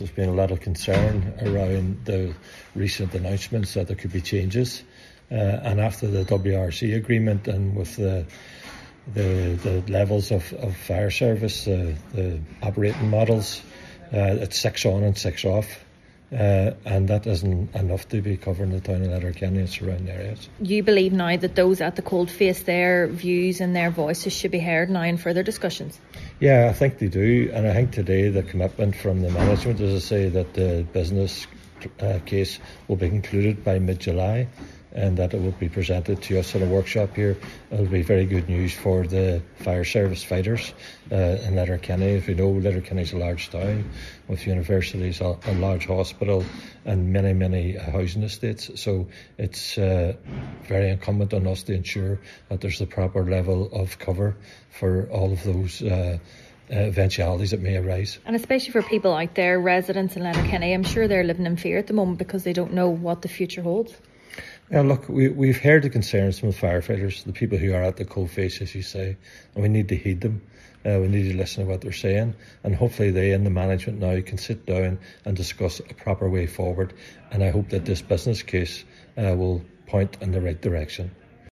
Councillor McMonagle says it’s vital the service is properly resourced: